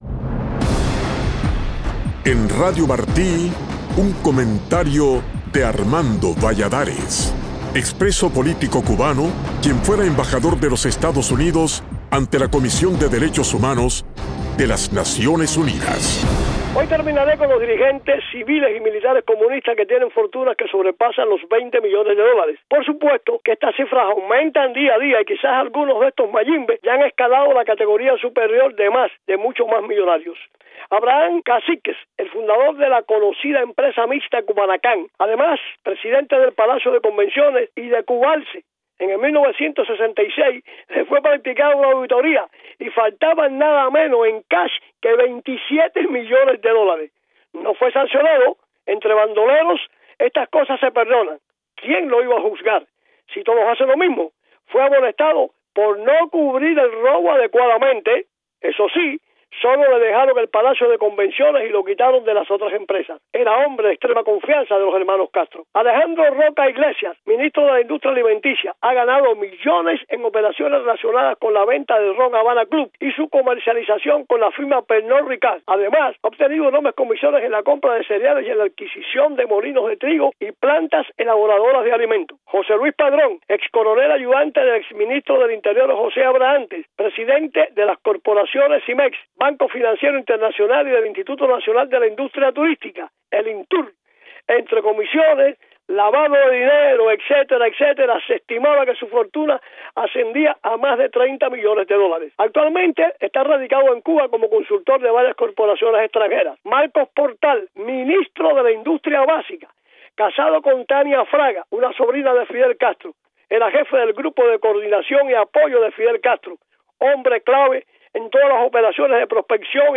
El embajador Valladares continúa hoy su comentario sobre los dirigentes militares y civiles en Cuba que tienen fortunas de más de 20 millones de dólares, una cifra que aumenta cada día.